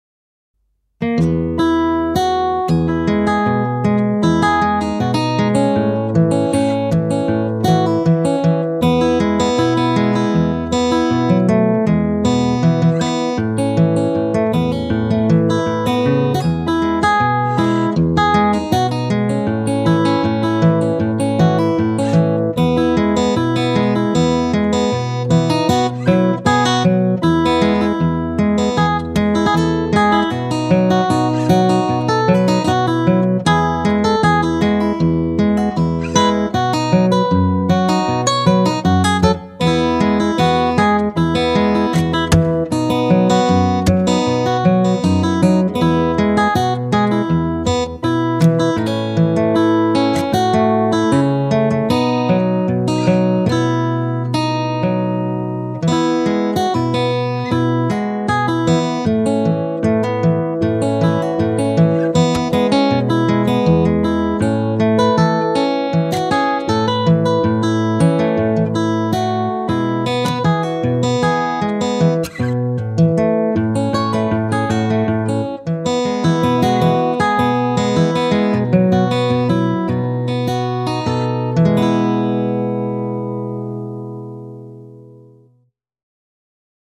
Largo [0-10] amour - guitare classique - - -